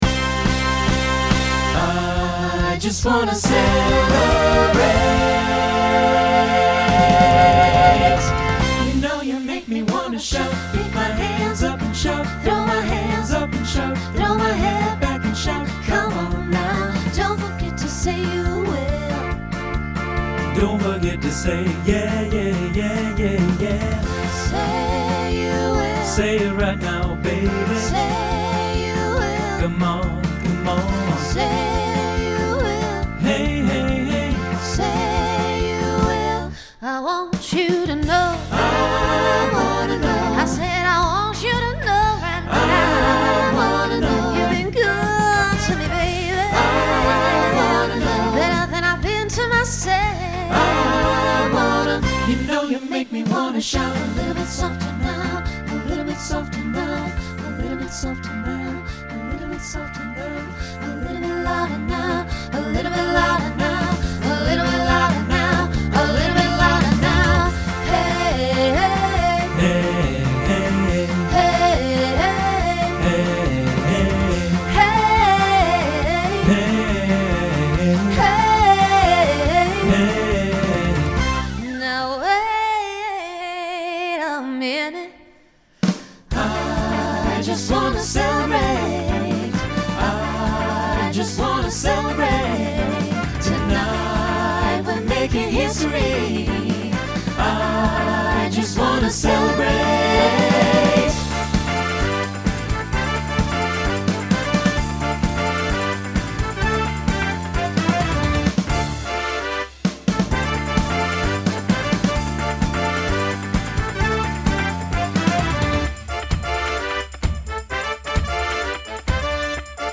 Pop/Dance , Rock Instrumental combo
Voicing SATB